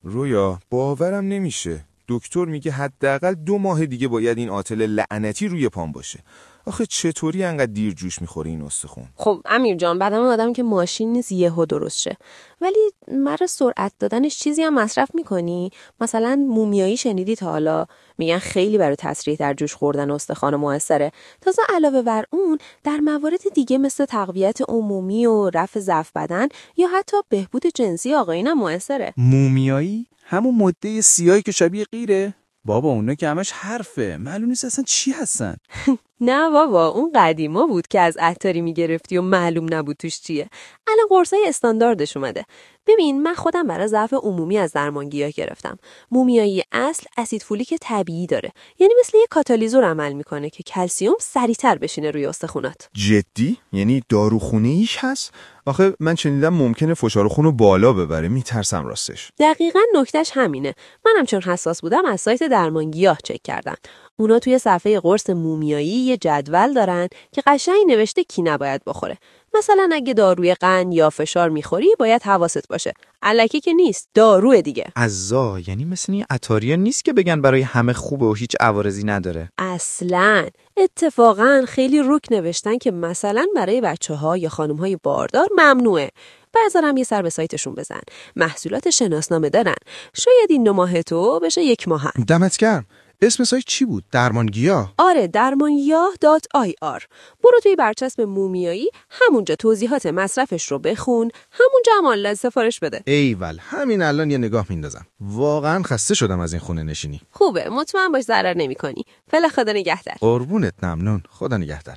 در این گفتگوی کوتاه ۹۰ ثانیه‌ای، به همراه کارشناسان درمانگیاه بررسی کردیم که چطور این اکسیر طبیعی کوهستان، سرعت ترمیم بافت‌های آسیب‌دیده را دوبرابر می‌کند.